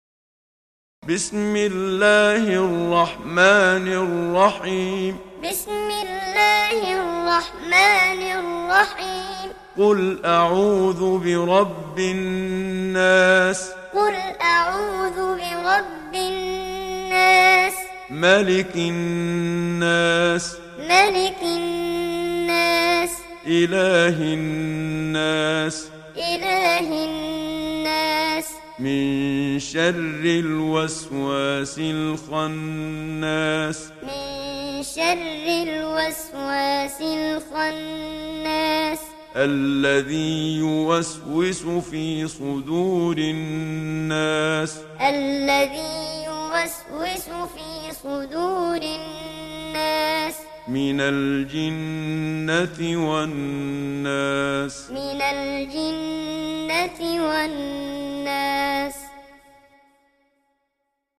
Download Surah An Nas Muhammad Siddiq Minshawi Muallim